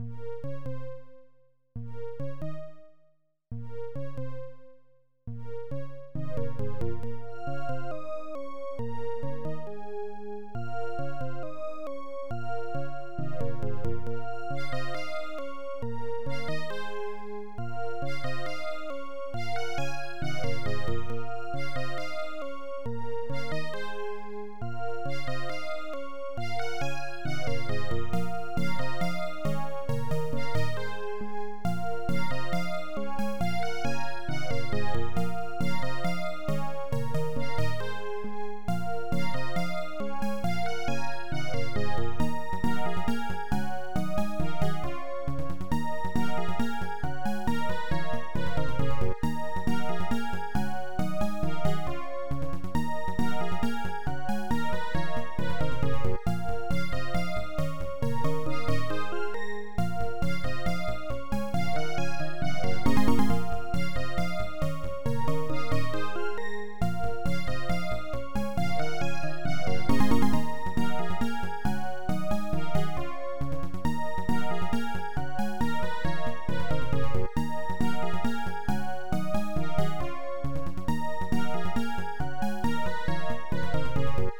HSC AdLib Composer